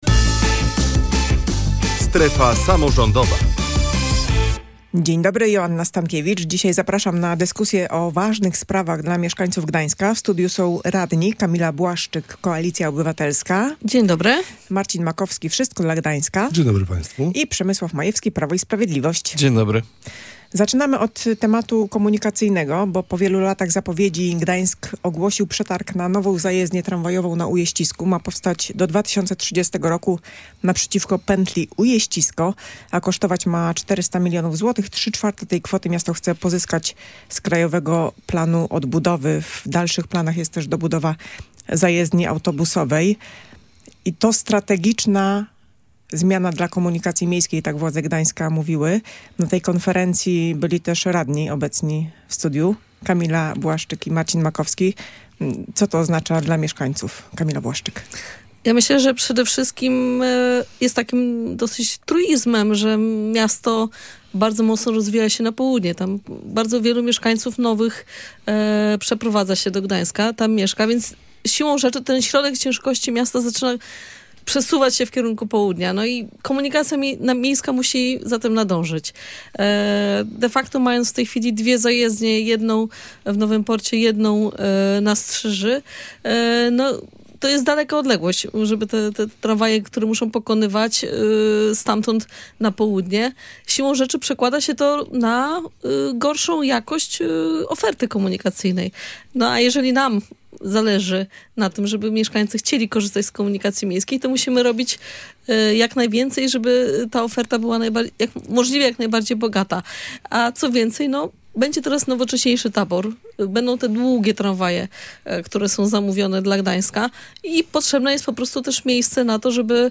Posłuchaj opinii radnych